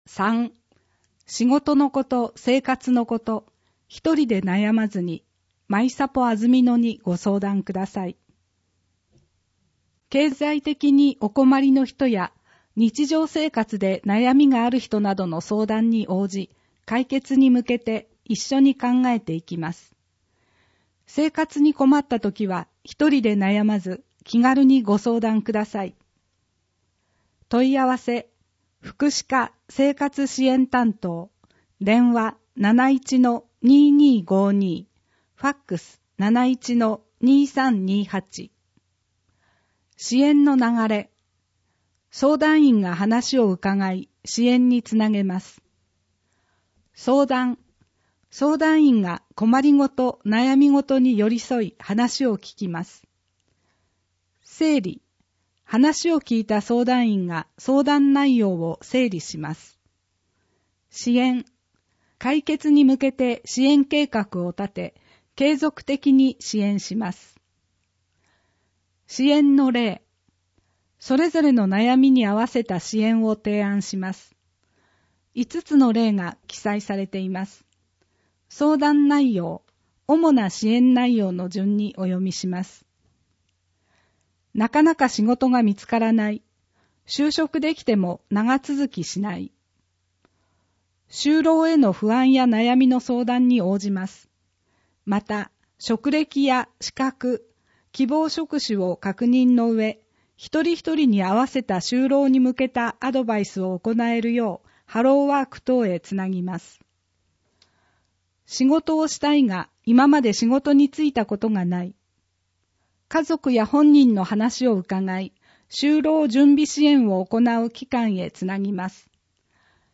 「広報あづみの」を音声でご利用いただけます。